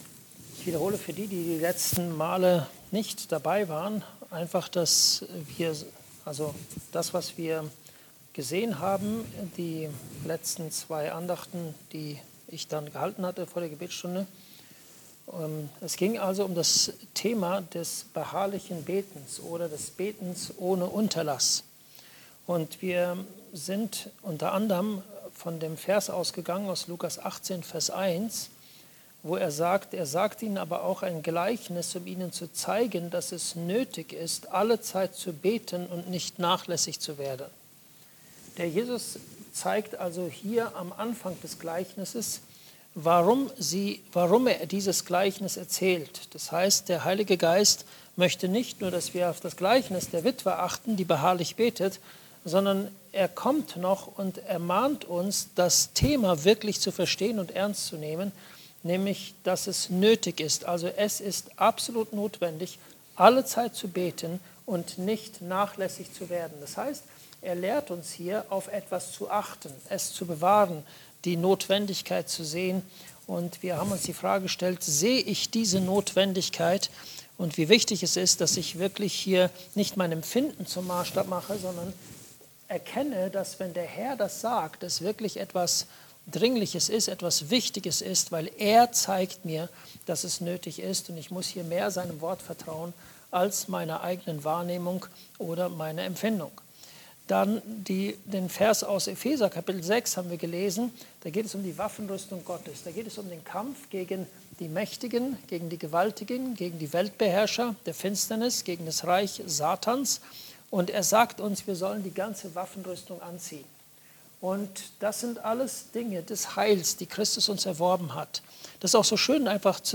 Wachet und betet! (Andacht Gebetsstunde)